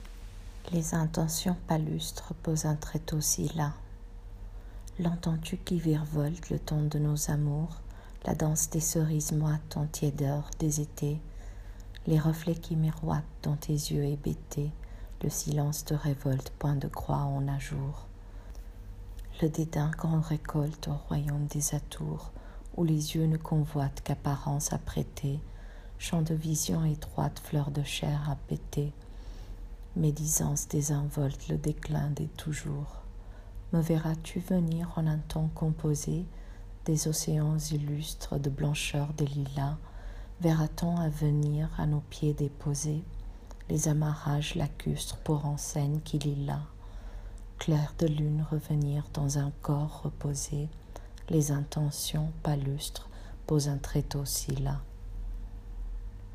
Lecture du poème: